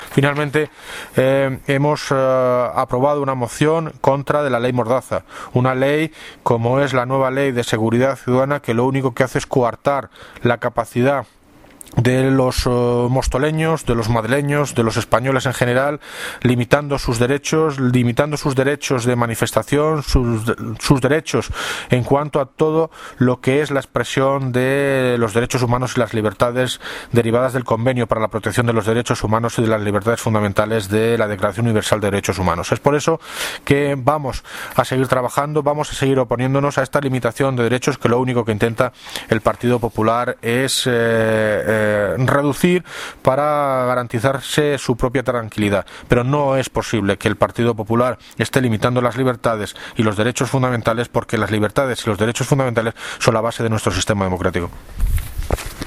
Audio de David Lucas, Alcalde de Móstoles